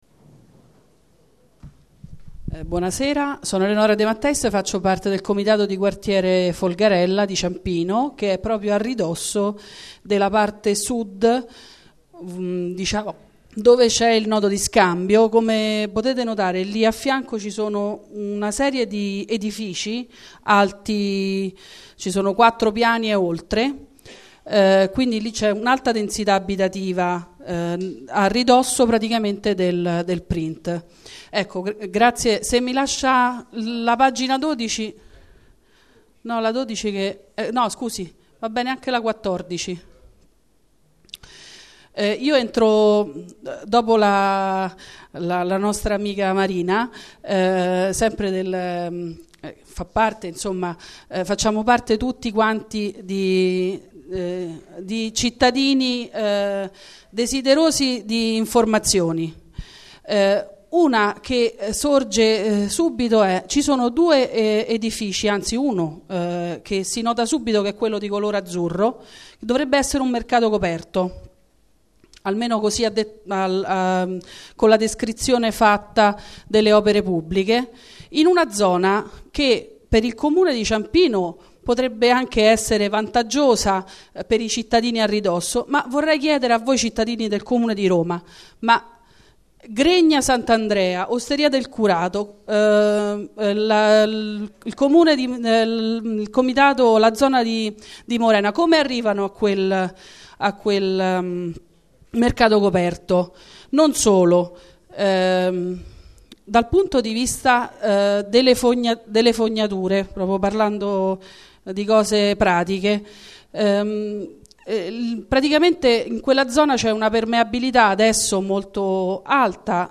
Registrazione integrale dell'incontro svoltosi il 15 luglio 2014 presso la Sala Rossa del Municipio VII, in Piazza di Cinecittà, 11